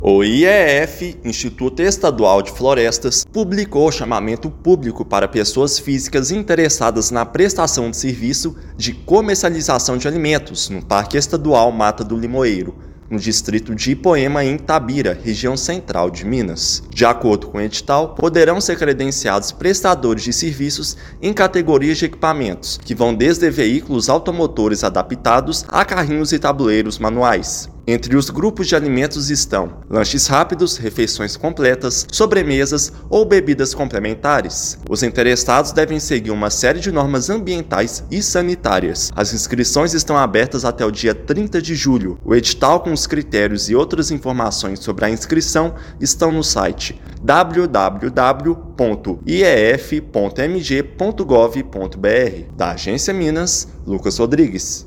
Oportunidade beneficia pequenos empreendedores locais e fortalece turismo sustentável na região de Itabira. Ouça matéria de rádio.